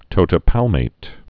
(tōtĭ-pălmāt)